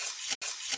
胶带.mp3